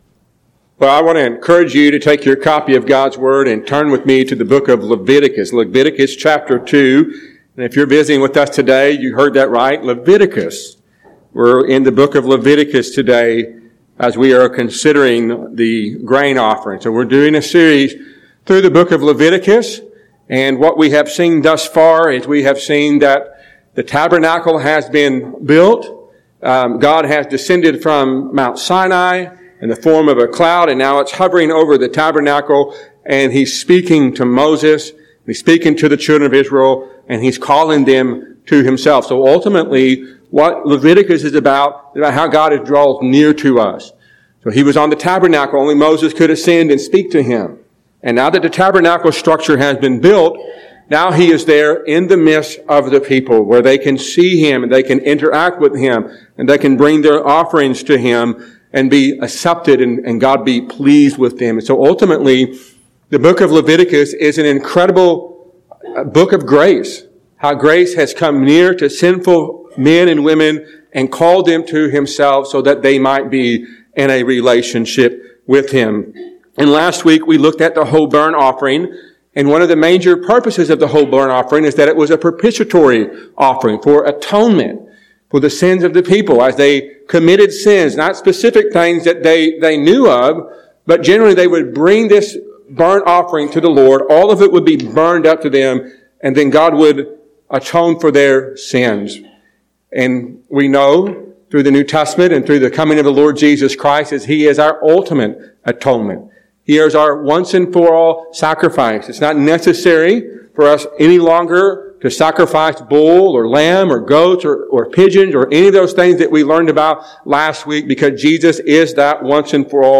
A sermon from Leviticus 2.